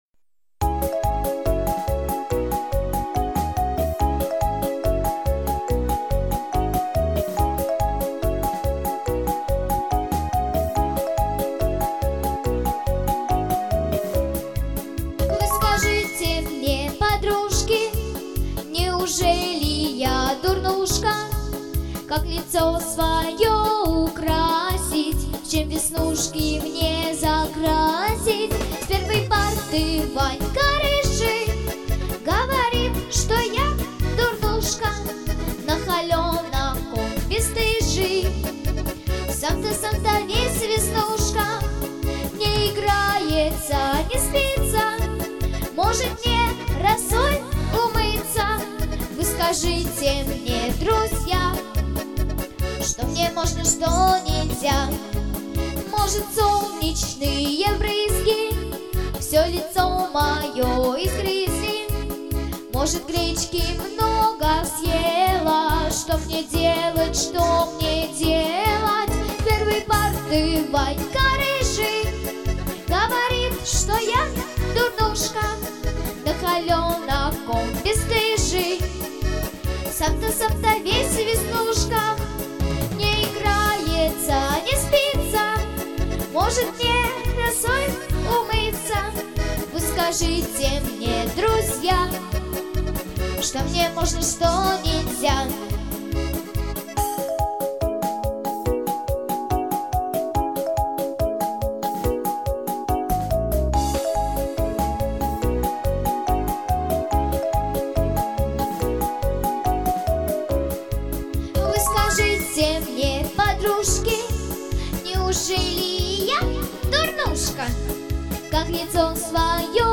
• Жанр: Детские песни
шуточная